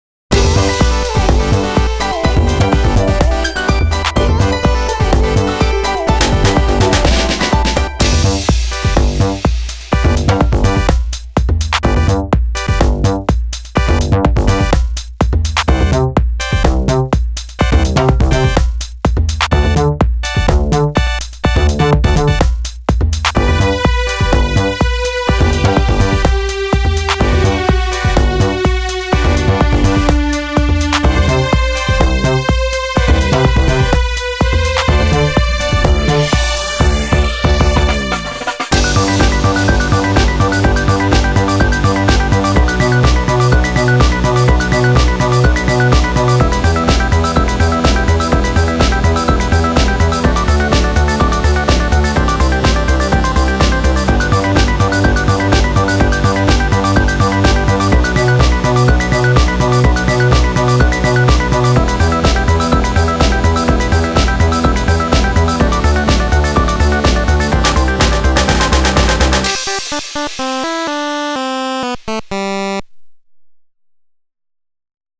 あかるい